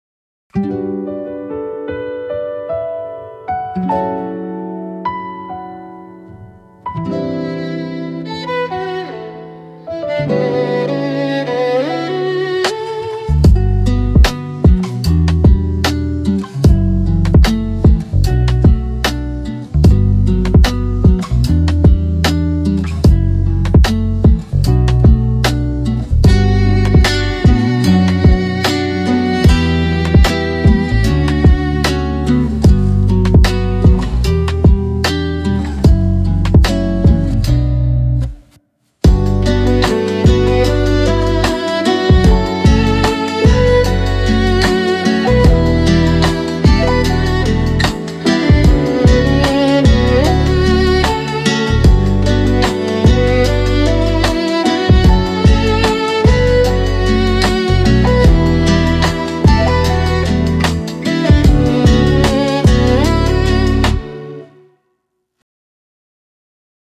Oriental
2.55 MB Oriental Nostalgic Sentimental よかったらシェアしてね！